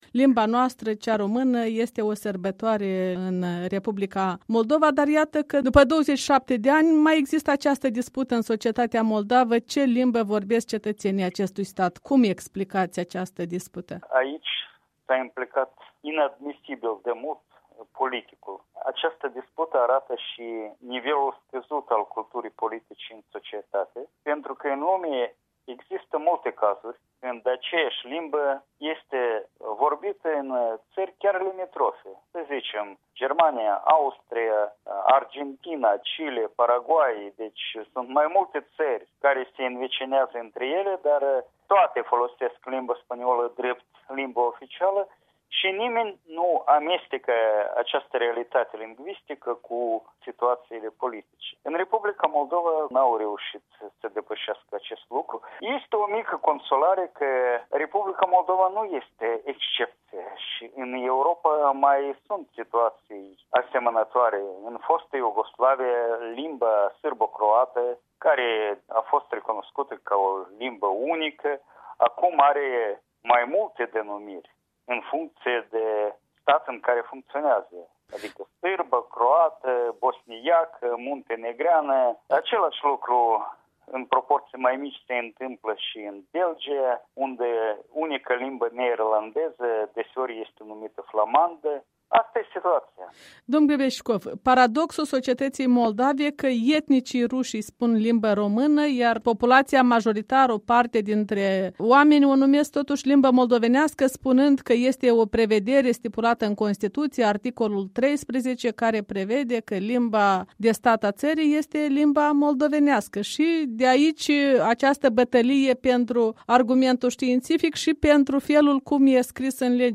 Un dialog înainte de sărbătoarea limbii române de la Chișinău